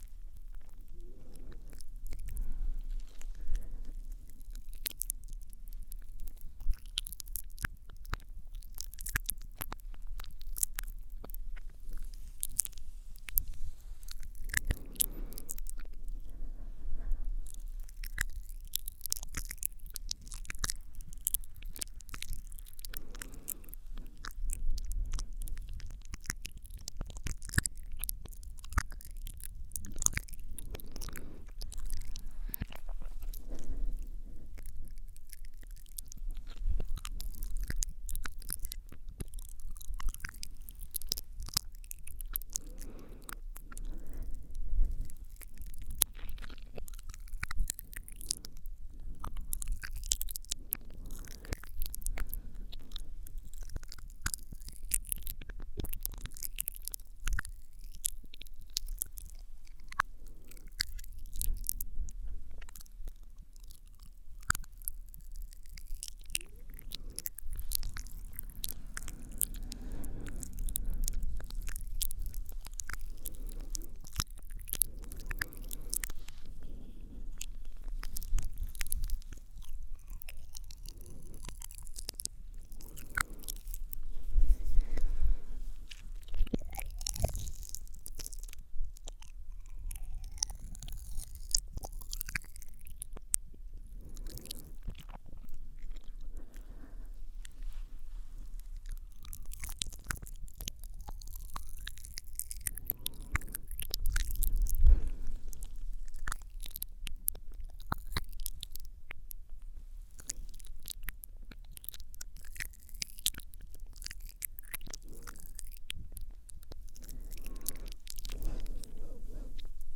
Свист, щелчки языком, цоканье, шепот, мычание и другие необычные звуковые эффекты.
Рот в реверсе